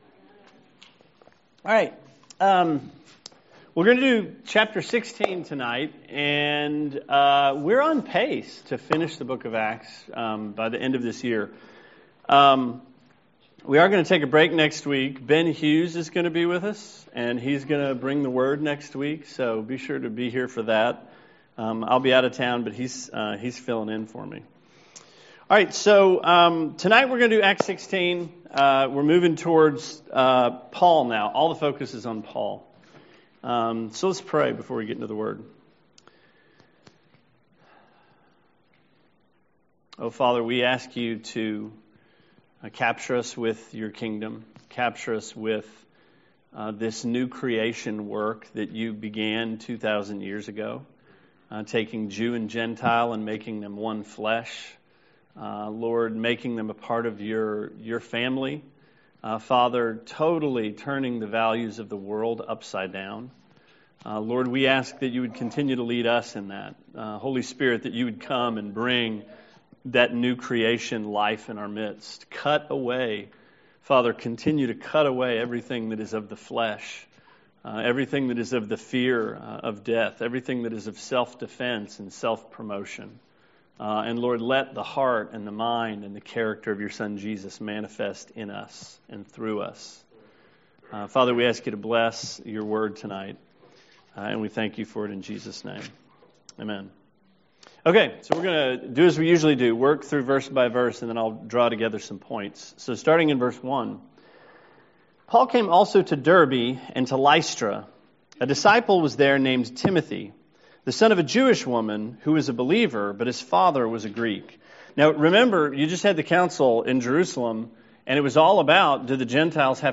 Sermon 7/1: Acts 16